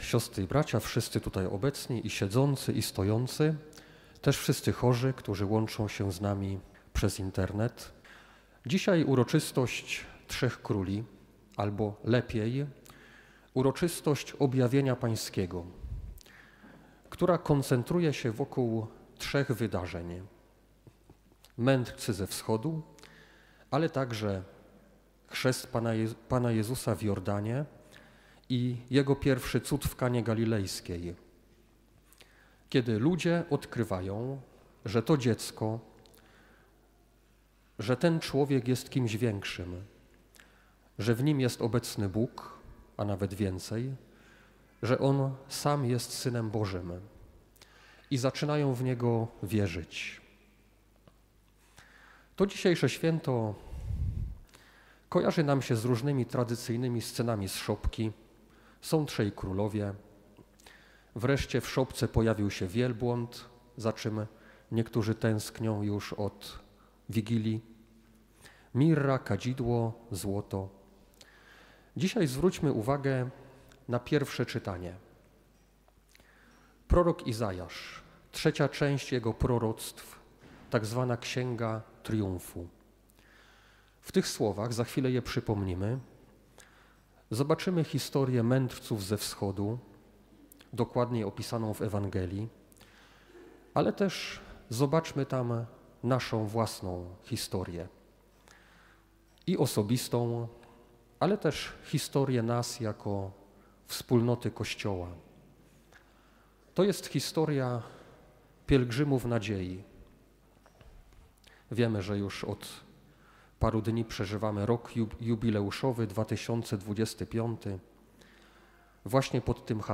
W święto Objawienia Pańskiego uroczystej Mszy św. odprawionej w Katedrze wrocławskiej przewodniczył bp Maciej Małyga.
homilia-bp-Malyga.mp3